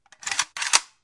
A K47 " ak47枪膛弹
标签： 39毫米 62 7 攻击 卡拉什尼科夫 负载 北方工业 47 样本 AK 声音 子弹 免费 WAV 步枪
声道立体声